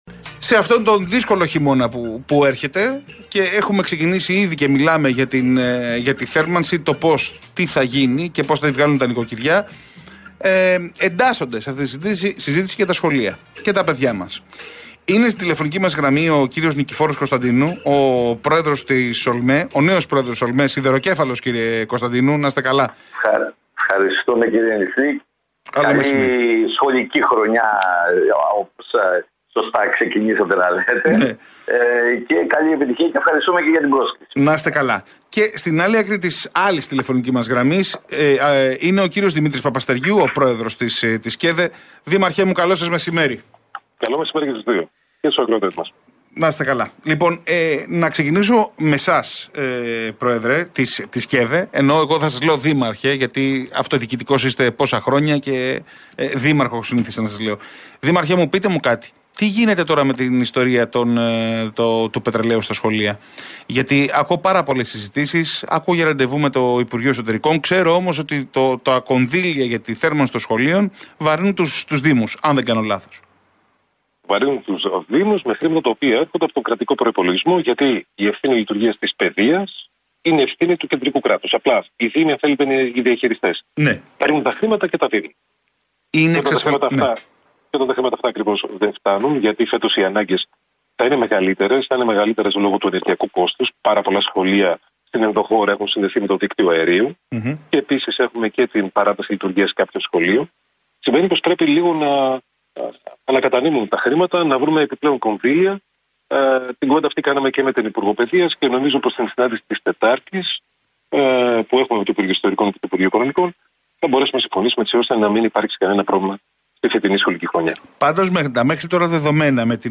ο πρόεδρος της ΚΕΔΕ, Δημήτρης Παπαστεργίου μίλησε για το θέμα στον RealFm 97,8